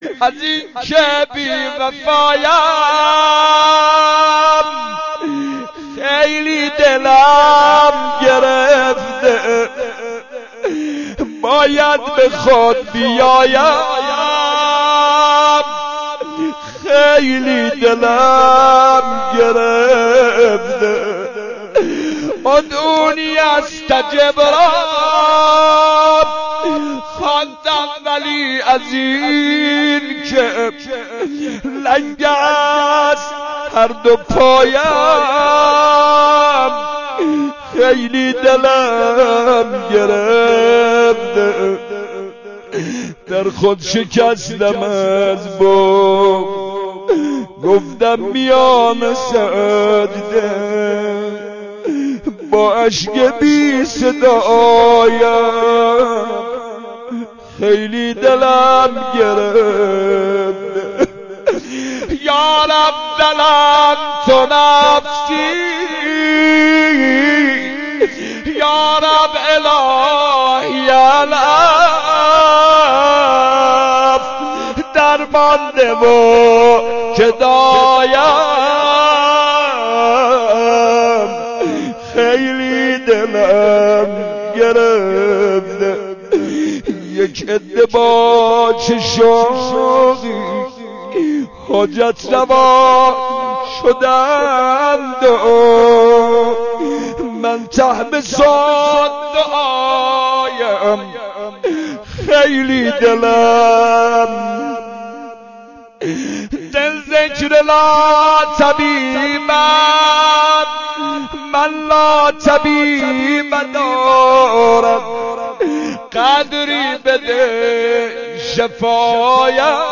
مناجات، پیشنهاد دانلود.